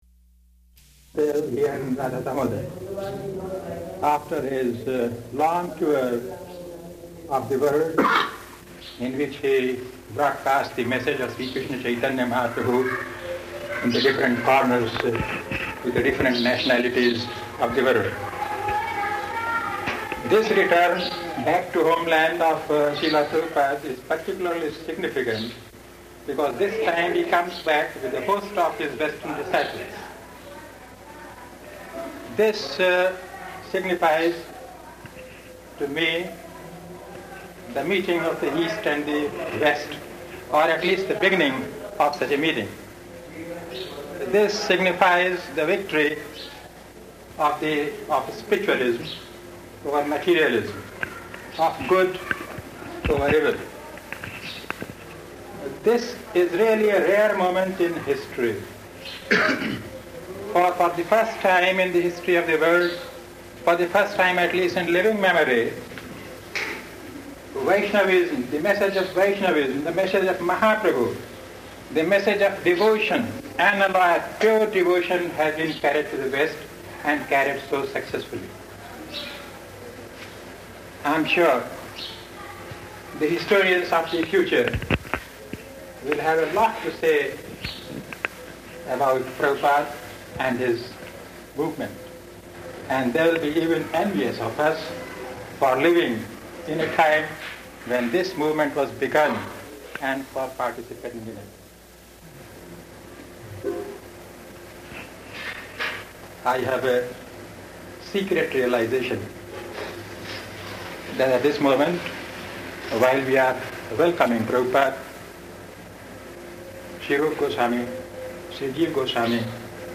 Lecture
Type: Lectures and Addresses
Location: Vṛndāvana